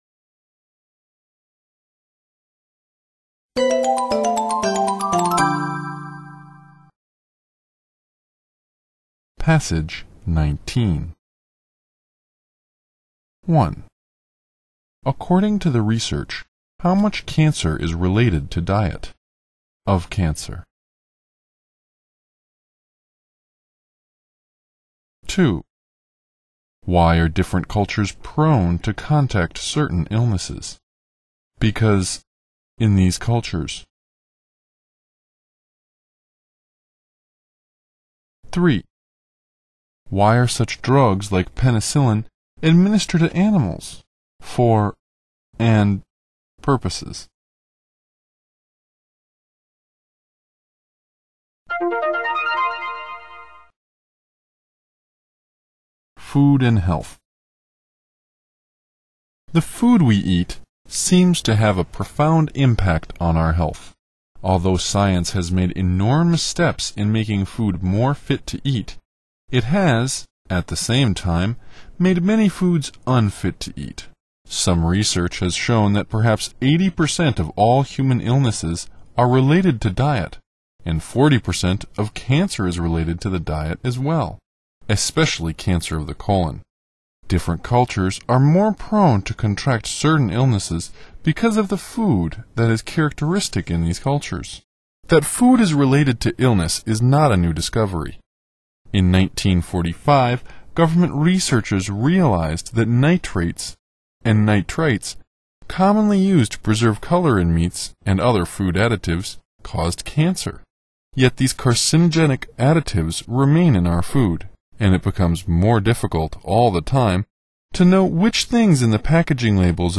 常考时文朗诵19 听力文件下载—在线英语听力室